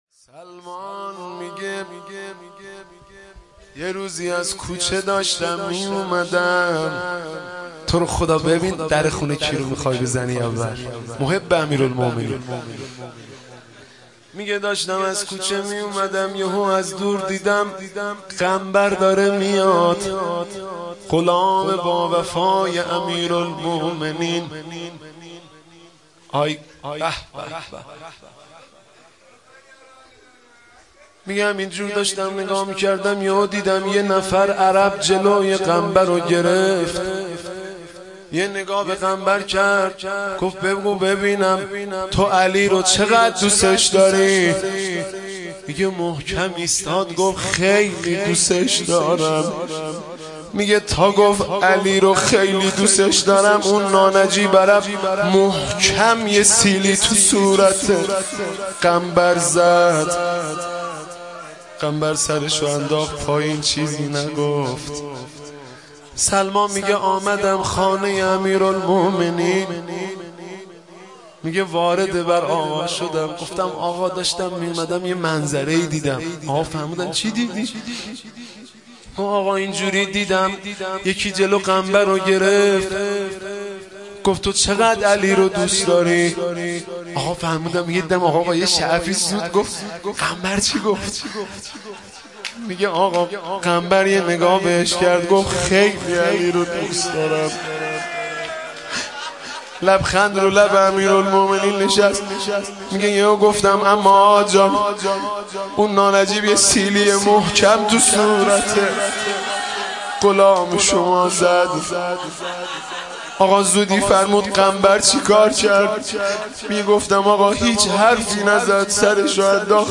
روضه شهادت حضرت علی مهدی رسولی شب 21 رمضان 97
مداحی جدید حاج مهدی رسولی شب بیست و یکم رمضان ۹۷